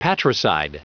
Prononciation du mot patricide en anglais (fichier audio)
Prononciation du mot : patricide